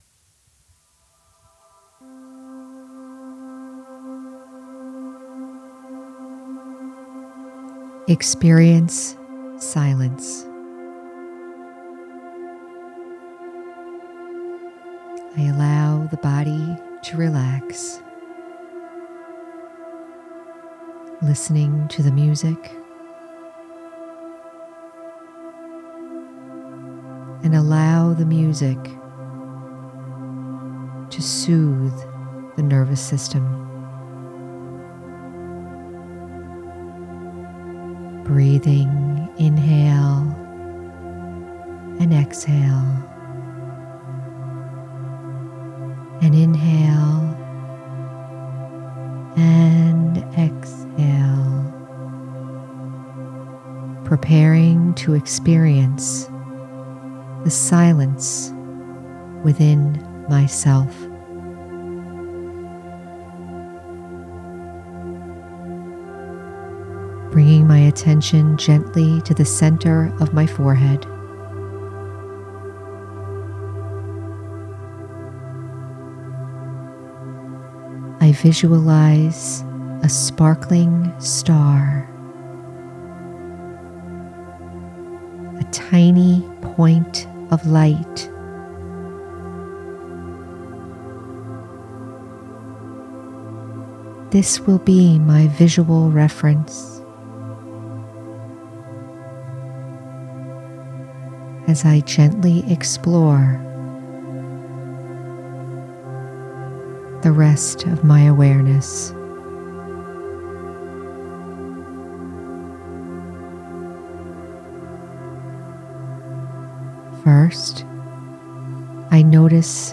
Experience the natural Silence in your own being with this gentle guided meditation and soft music.